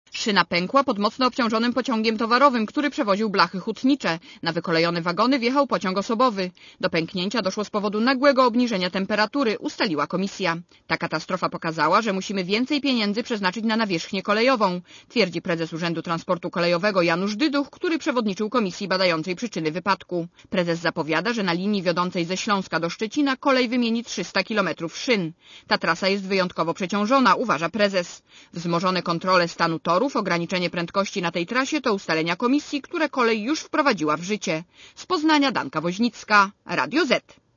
Posłuchaj relacji reporterki Radia Zet